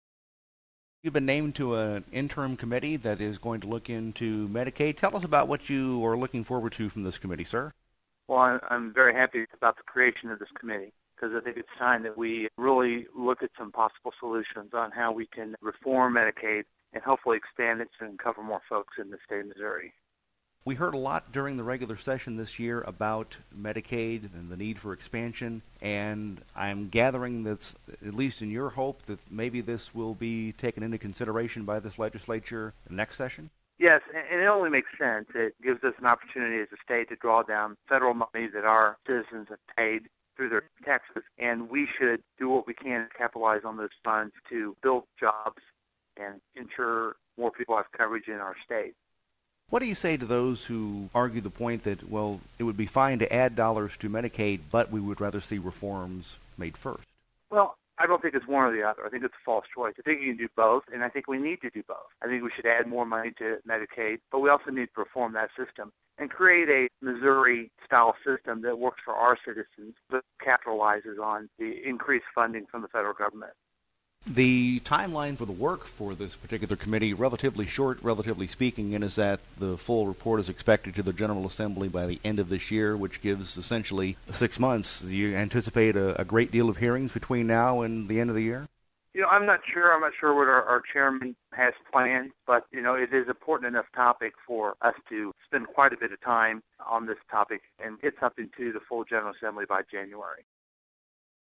The audio below is a full-length interview with Sen. LeVota — also available via podcast — for the week of June 10, 2013.